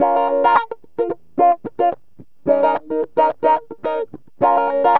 GTR 8 A#M110.wav